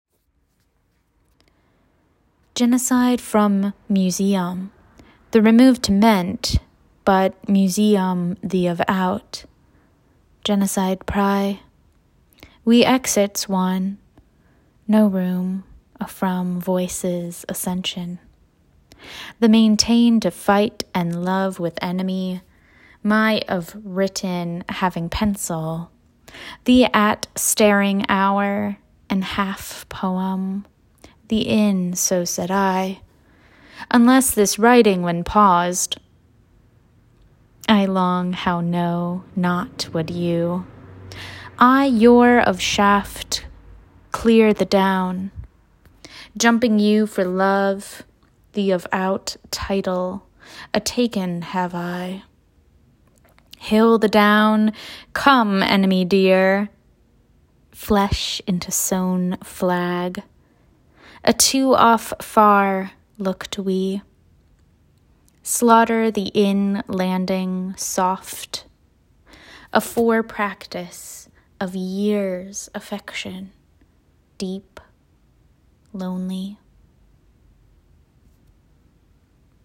Welcome back to Anonymous Grocer, a 30-day audio adventure in backwards poetry.